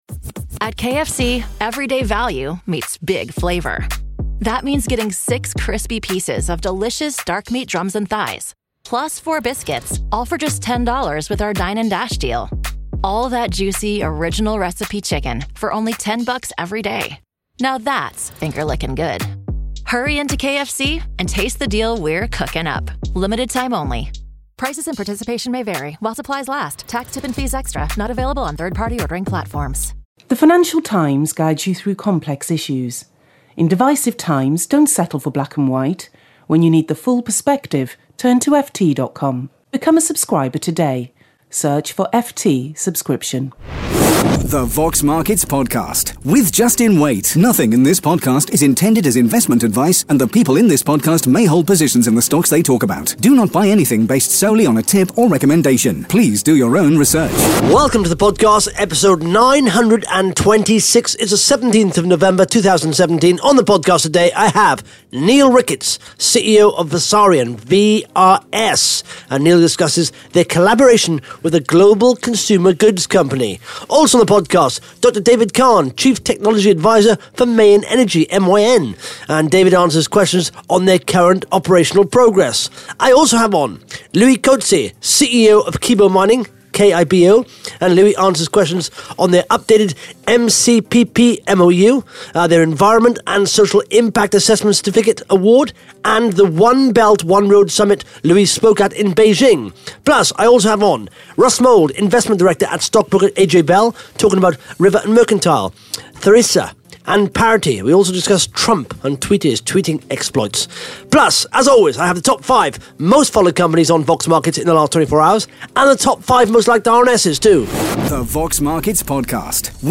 (Interview starts at 36 minutes 5 seconds) Plus the Top 5 Most Followed Companies on Vox Markets in the last 24 hours & the Top 5 Most Liked RNS’s on Vox Markets in the last 24 hours.